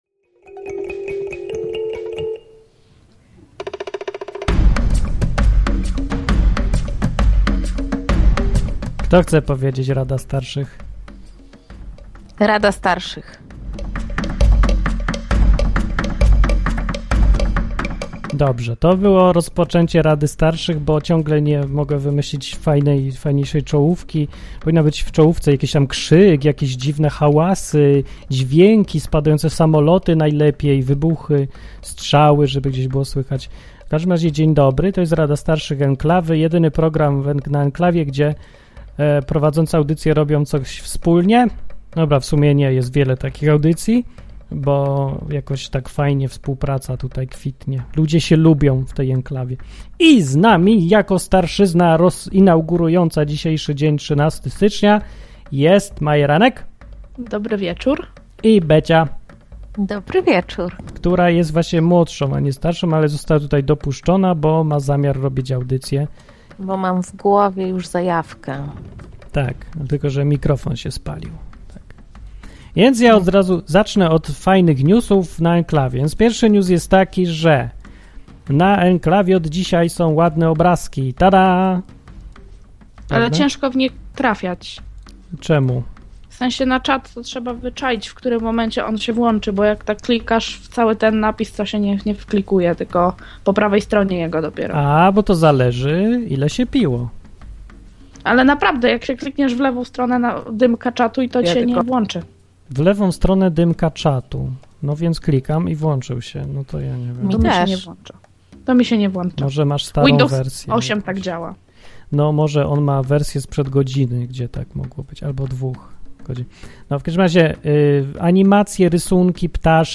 Dziś macie okazję przekonać się jak wygląda audycja na żywo prowadzona przez same kobiety.